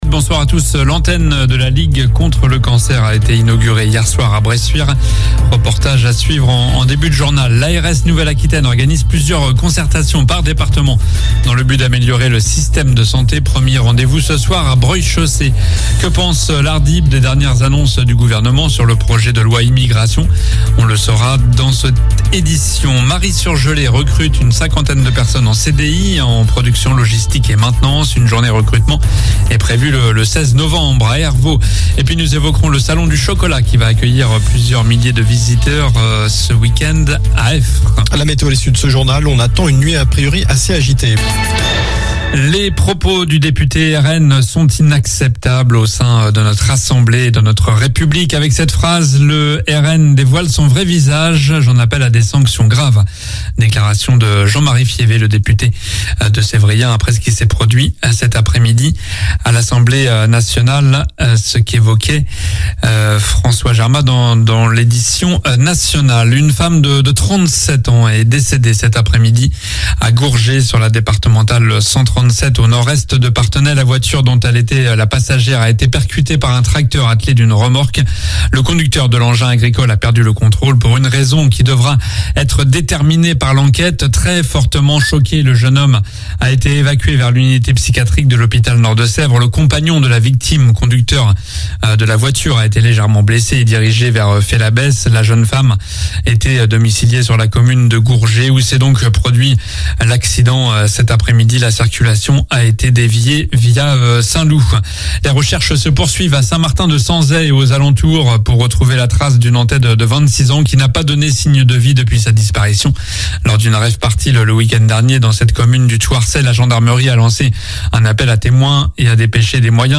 Journal du jeudi 03 novembre (soir)